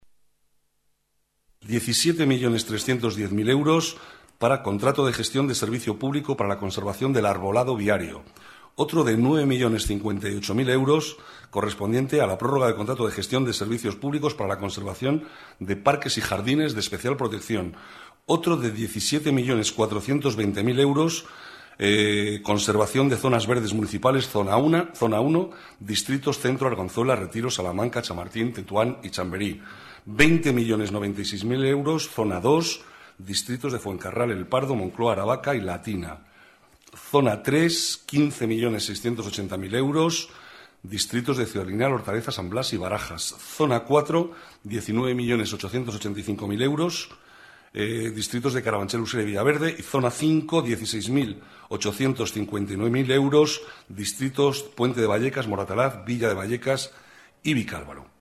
Nueva ventana:Declaraciones del vicealcalde, Manuel Cobo: Mantenimiento zonas verdes y arbolado